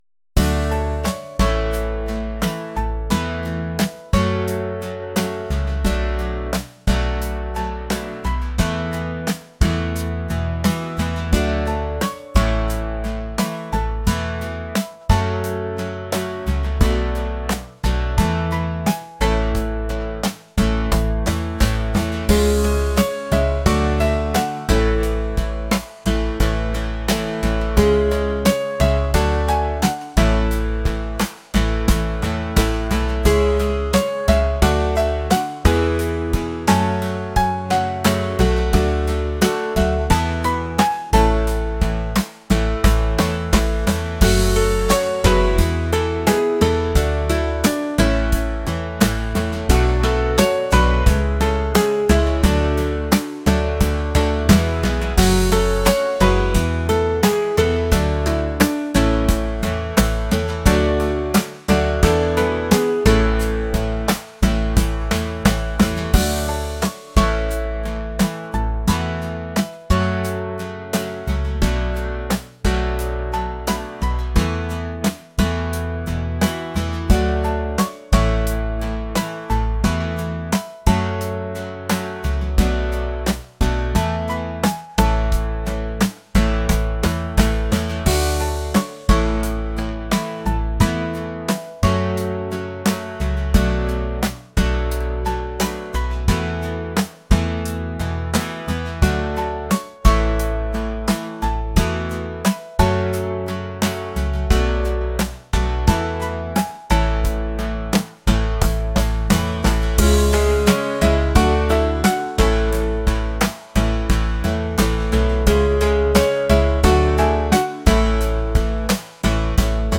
acoustic | pop | lofi & chill beats